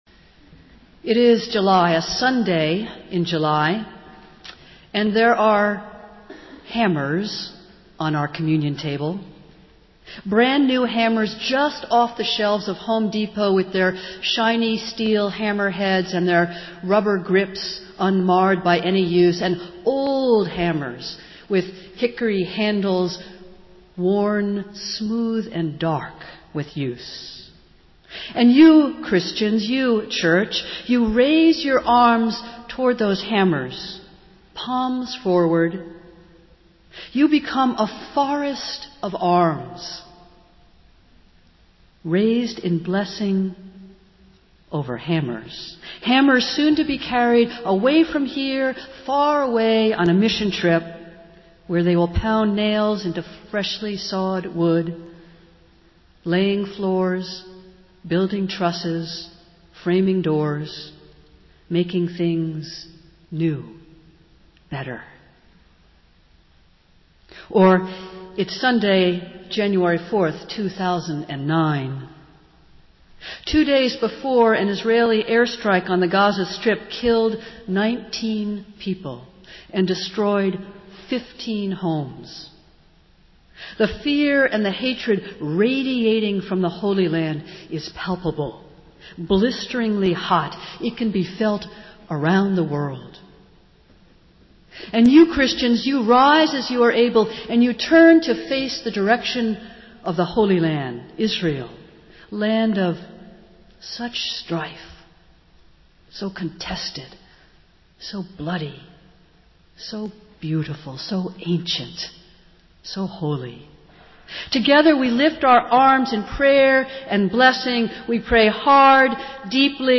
Festival Worship - Third Sunday after Epiphany